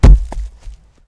collision_stone.wav